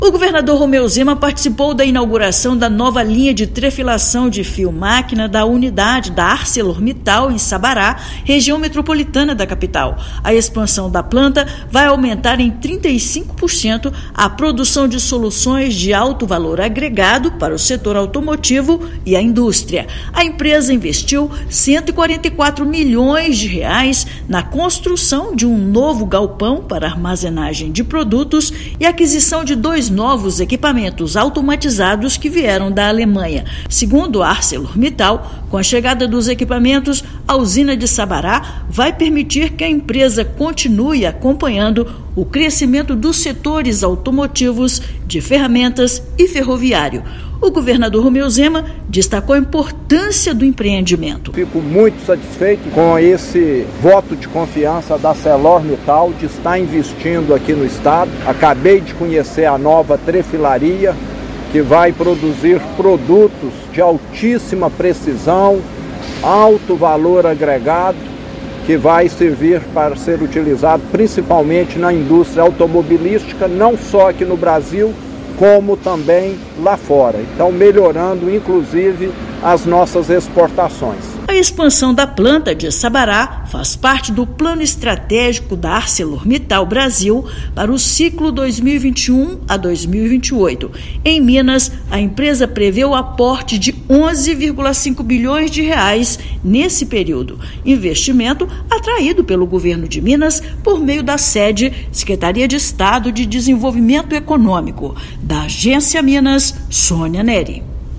A planta industrial recebeu investimentos de R$ 144 milhões para aumentar a capacidade de produção em 35%. Recurso faz parte dos R$ 11,5 bilhões que a empresa vai investir no estado até 2028. Ouça matéria de rádio.